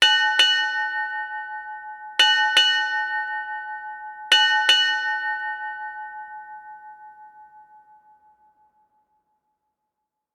Six Bells,Ship Time
6-bells bell ding maritime nautical naval sailing seafaring sound effect free sound royalty free Memes